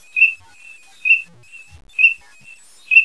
Kricka (Anas crecca).
kricka.wav